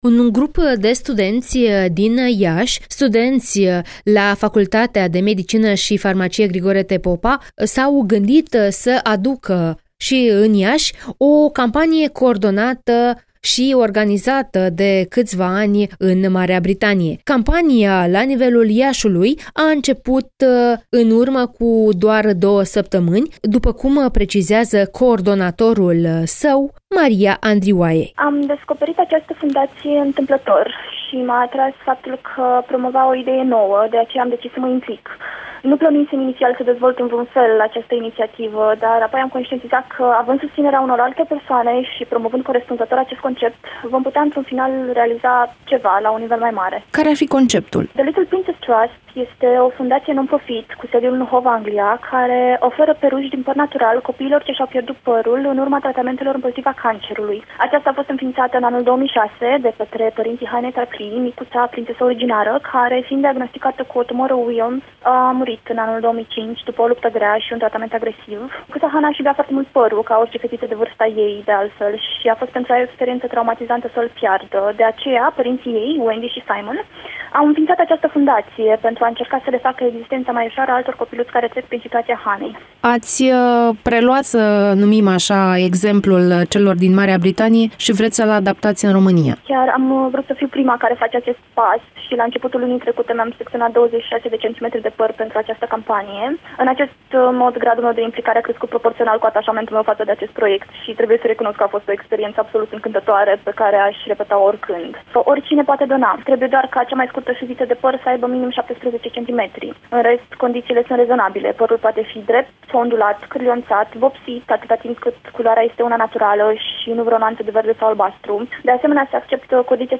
(INTERVIU) Donează păr pentru perucile copiilor care au cancer!